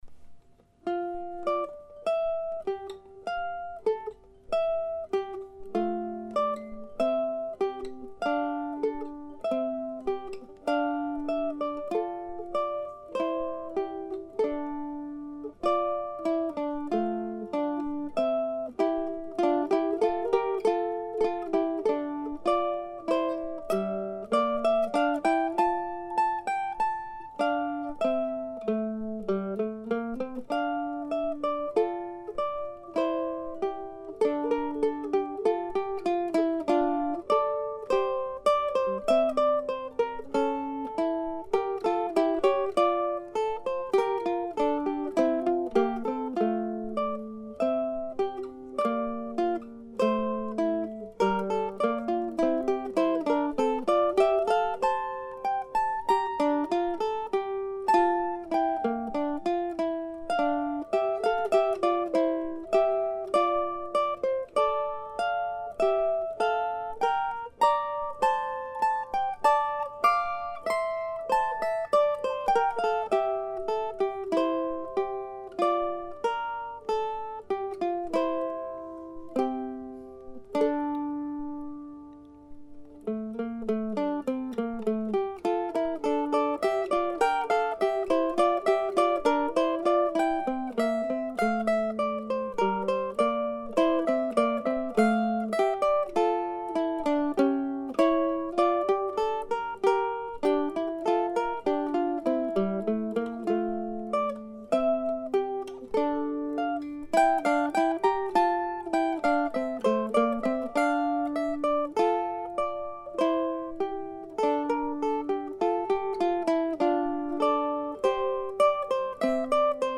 May 30, 2007 (slow duo) (
These three pieces were all recorded this morning with cold winds blowing outside.